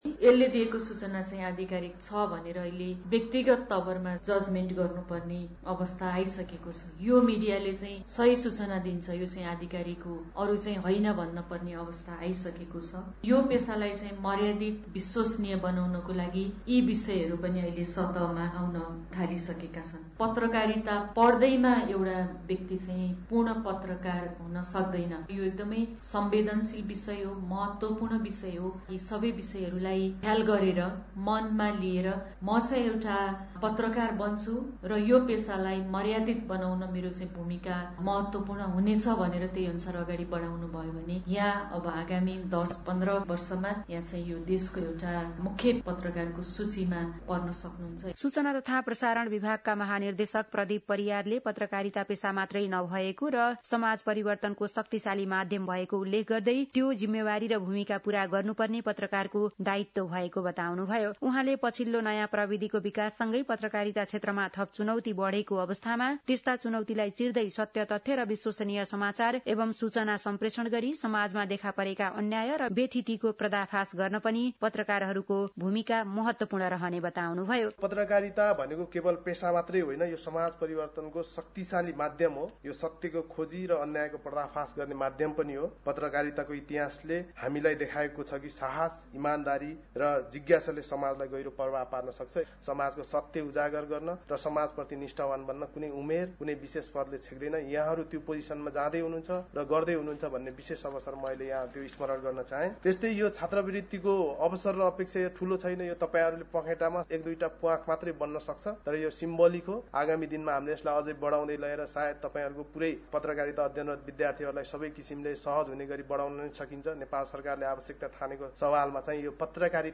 बिहान ८ बजेको अङ्ग्रेजी समाचार : १५ माघ , २०८१